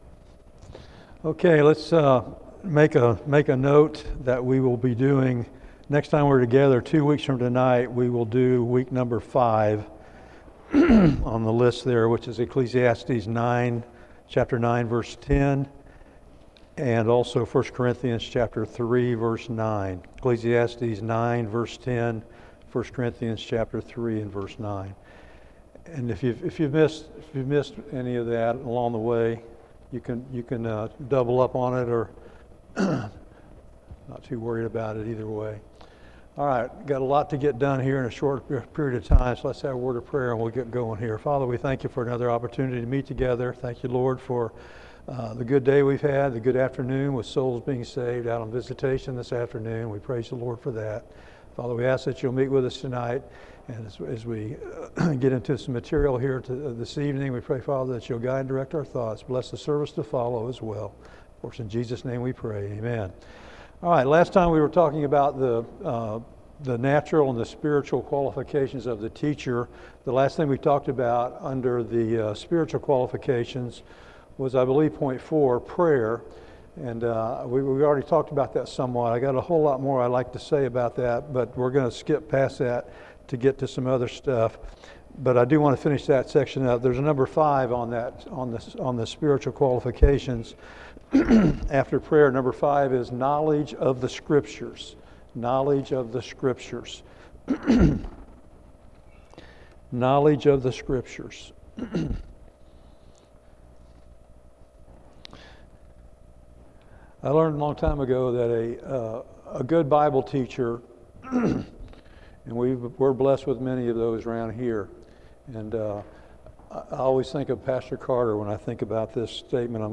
Service Type: Institute